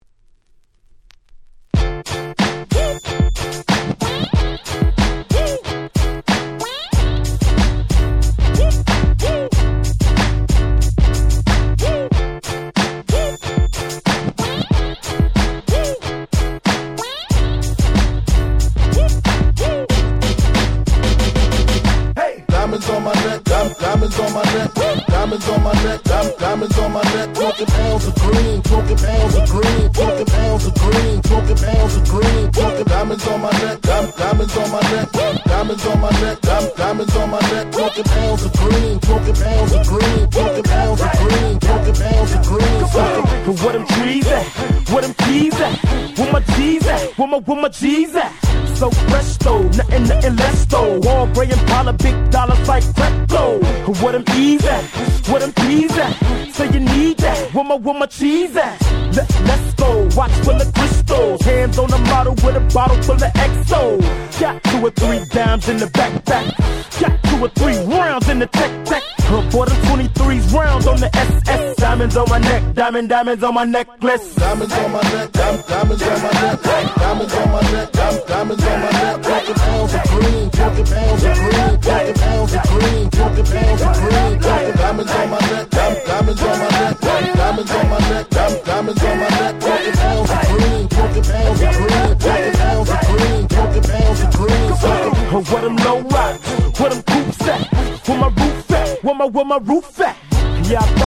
A-3に少し深い傷あり。
自分が当時好んで使用していた曲を試聴ファイルとして録音しておきました。